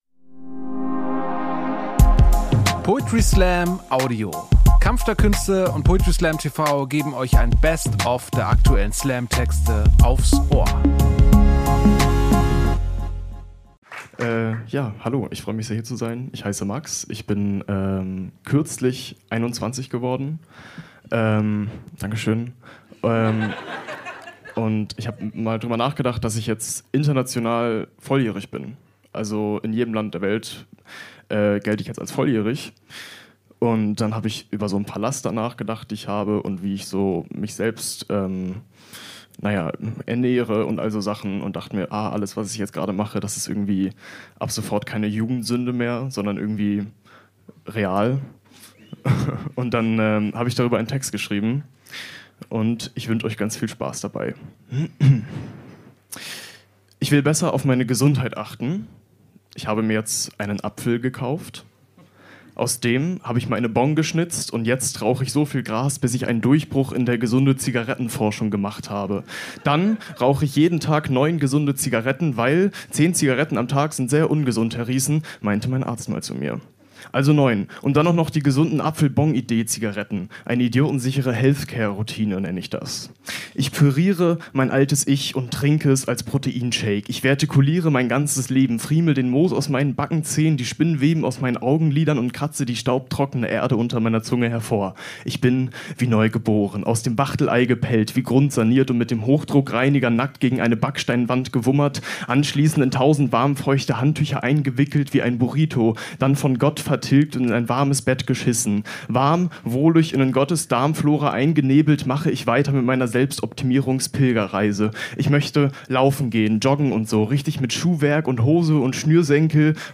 Stage: Birdland Club, Hamburg